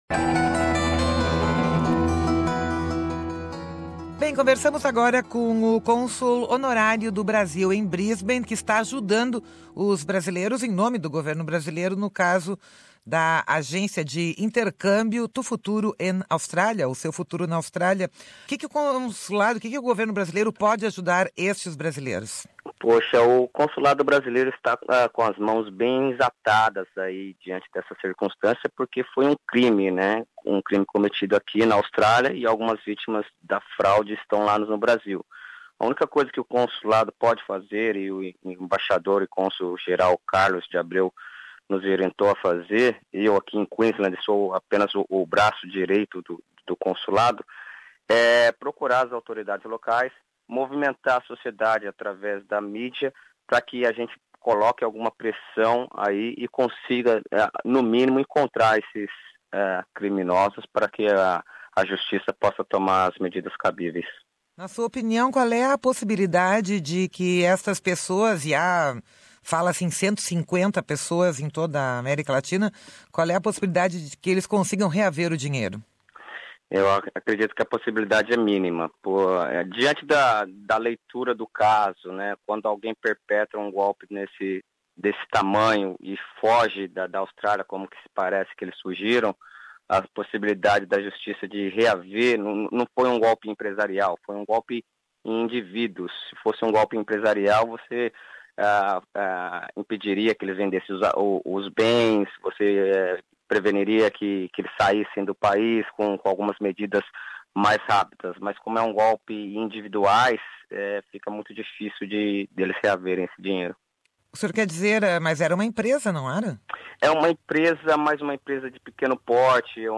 Ouça aqui a entrevista com Valmor Morais.